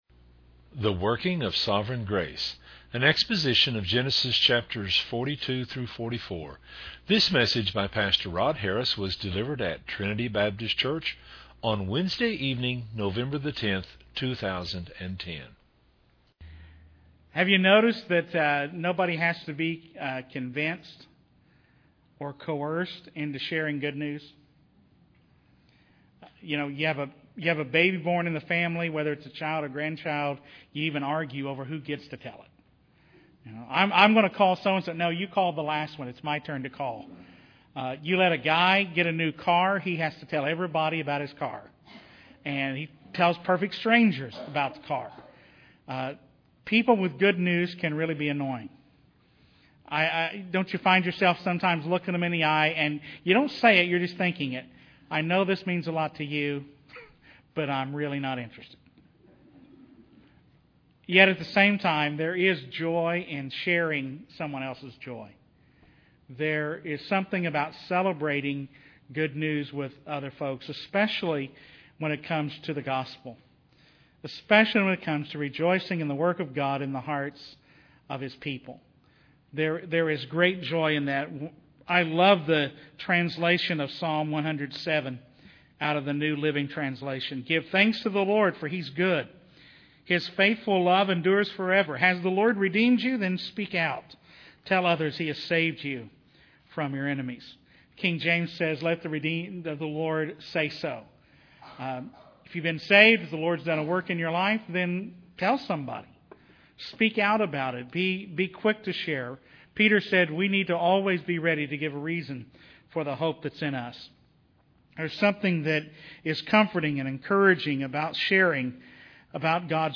delivered at Trinity Baptist Church on Wednesday evening